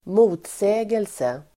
Uttal: [²m'o:tsä:gelse (el. -sej:-)]